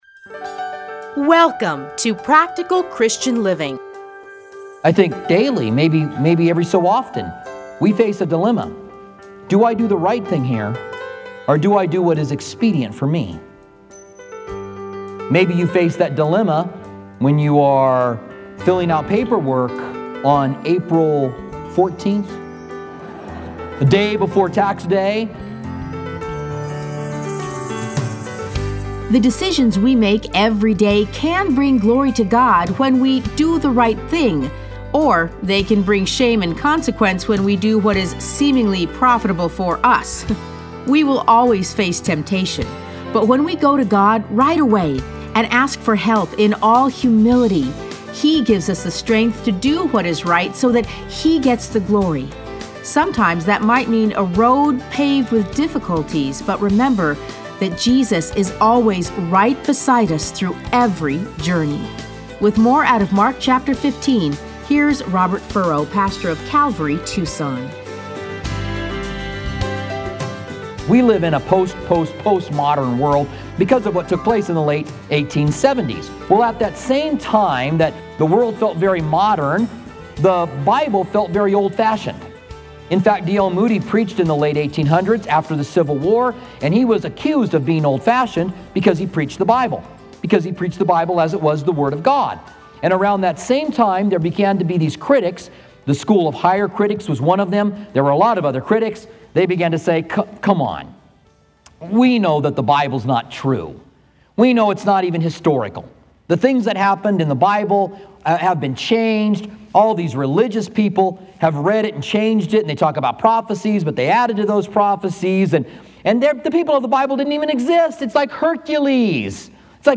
Listen to a teaching from Mark 15:1-20.